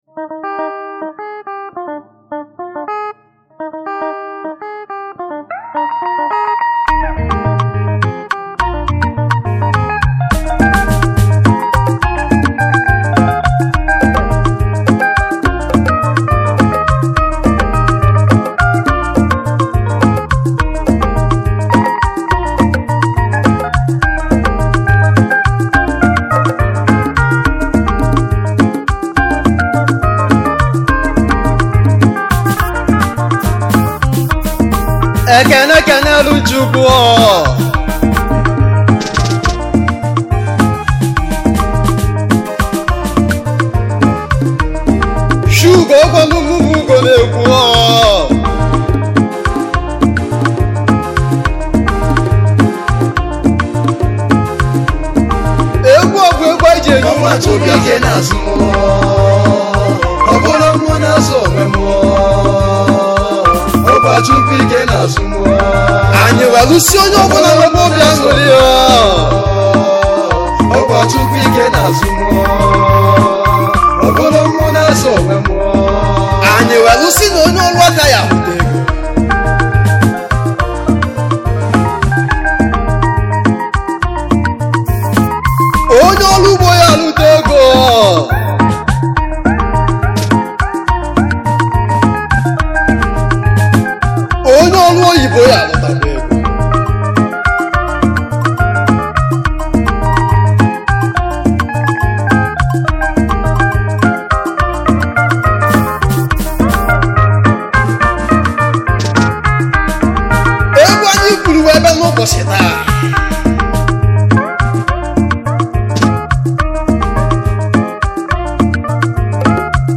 highlife music band